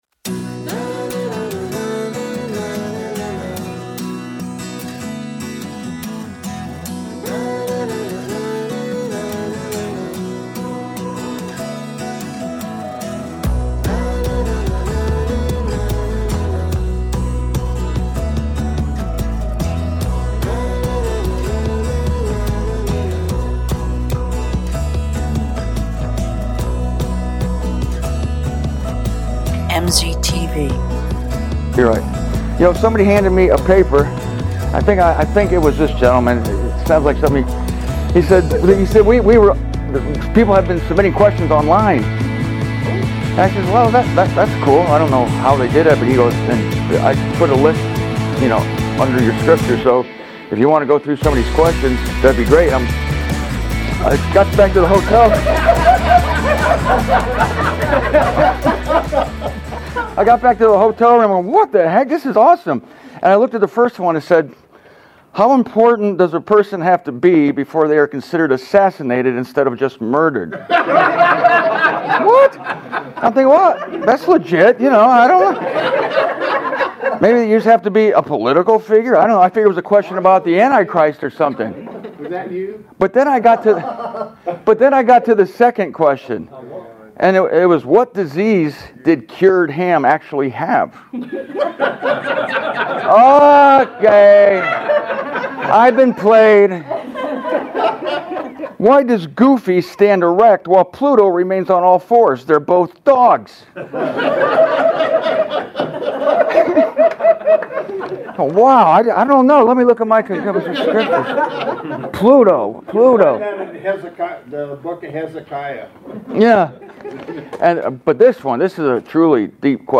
Comments from the Phoenix, AZ conference about the certainty of the snatching away.